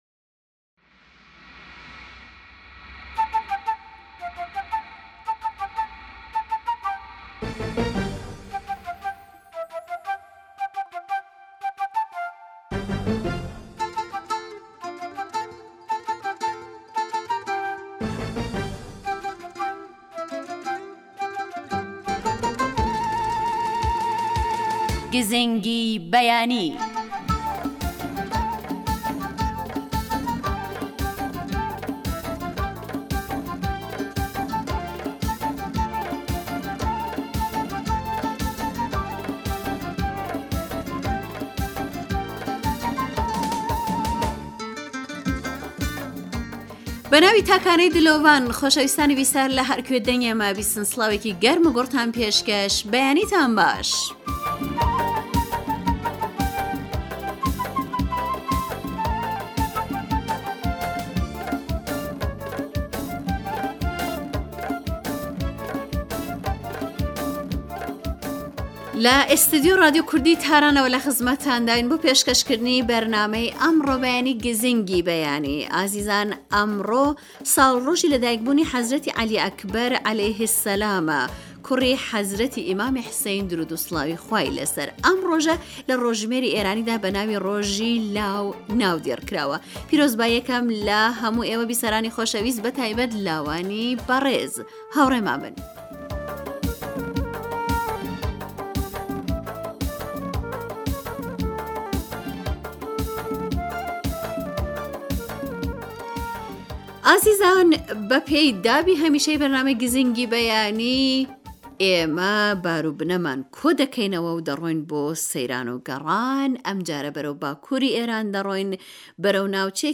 گزینگی بەیانی بەرنامەیێكی تایبەتی بەیانانە كە هەموو ڕۆژێك لە ڕادیۆ كەردی تاران بڵاو دەبێتەوە و بریتییە لە ڕاپۆرت و دەنگی گوێگران و تاووتوێ كردنی بابەتێكی پ...